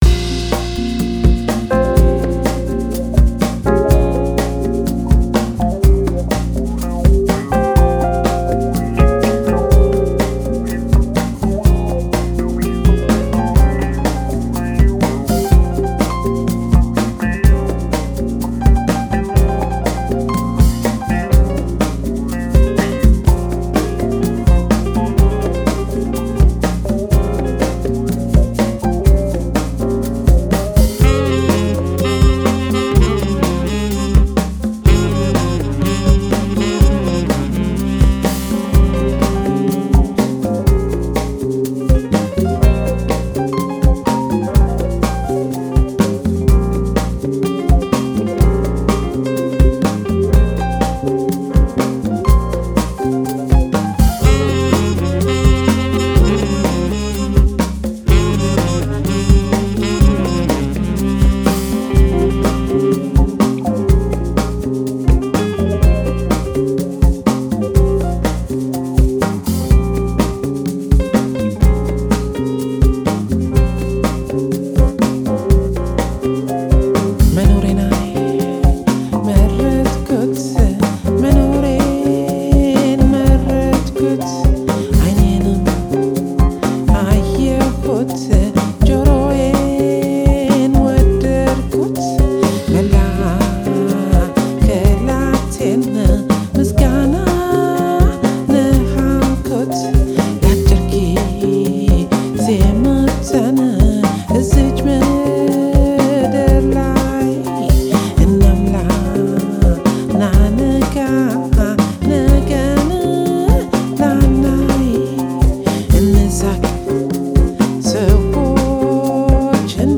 “Ethio-jazz” fusions
his vibraphone skills intact